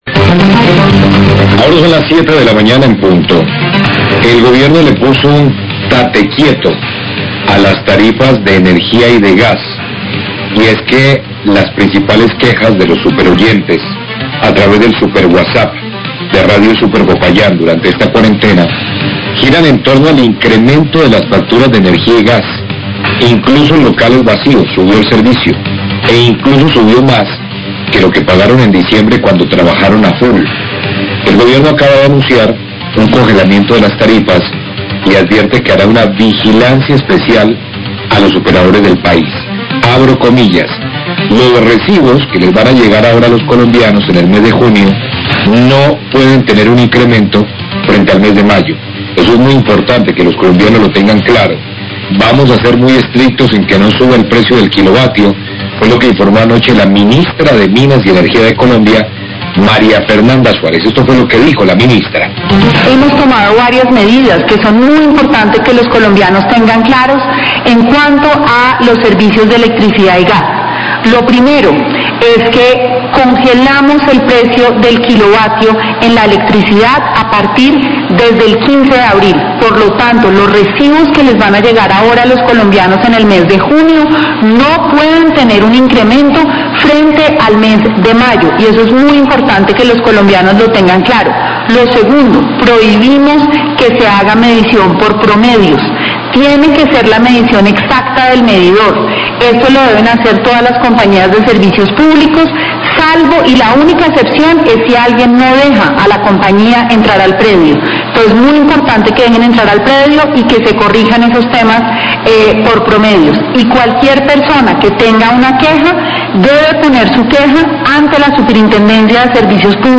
Radio
Se congeló el valor del kilovatio y está prohibido el cobro por promedio, se podrá diferir el pago de la factura. Declaraciones de la Ministra de Minas y Energía, María Fernanda Suárez y del Ministro de Vivienda, Jonathan Malagón.